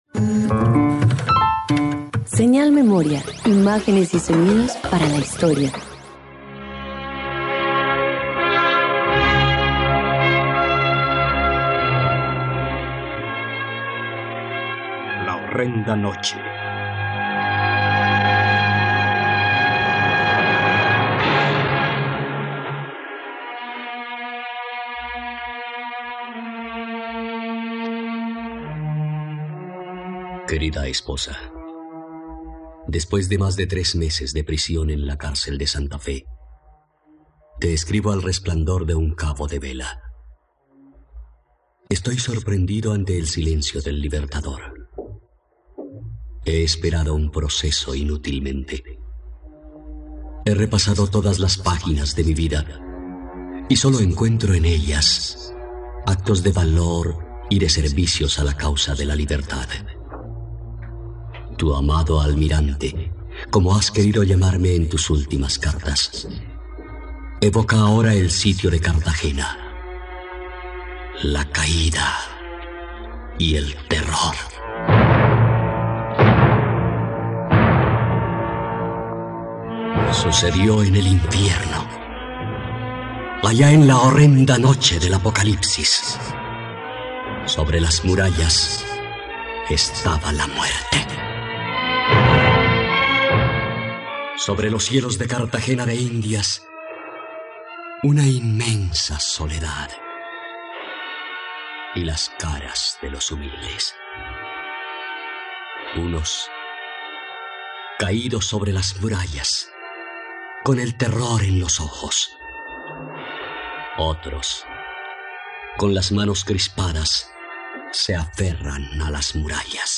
..Radioteatro. Sumérgete en "La horrenda noche", una obra que conmemora la Independencia de Cartagena.